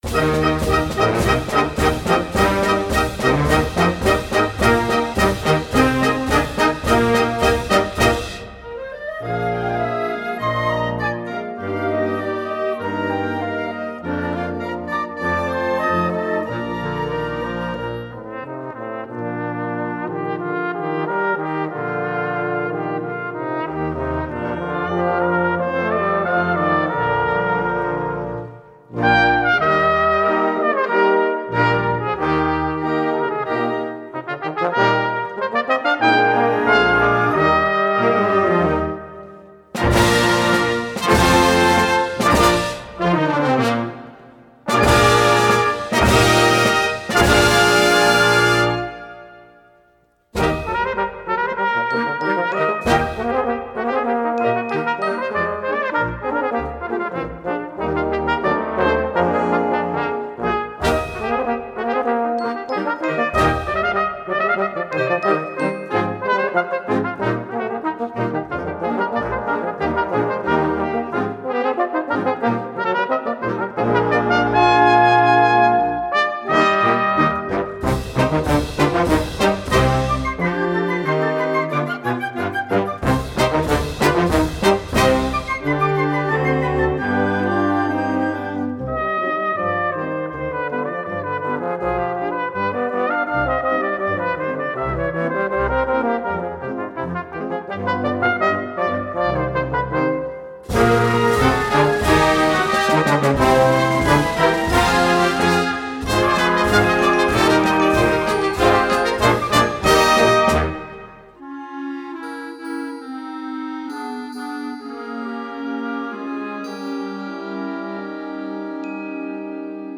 Voicing: Brass Duet and Concert Band